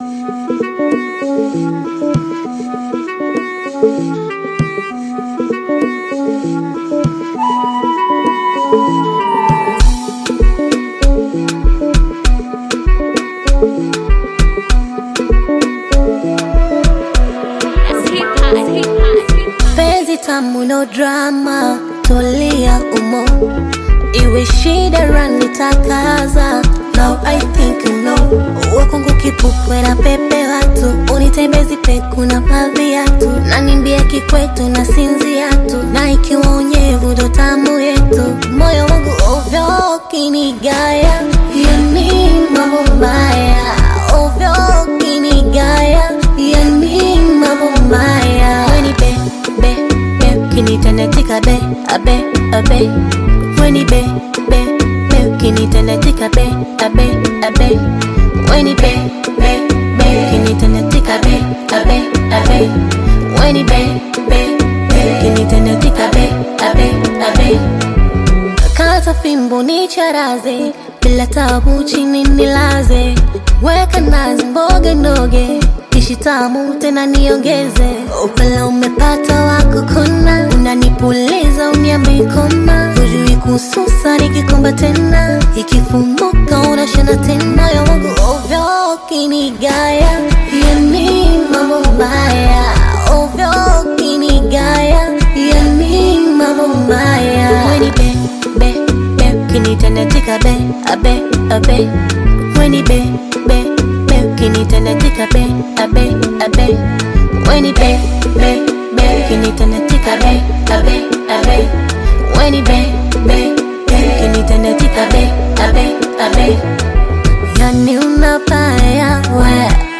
Bongo Flava
African Music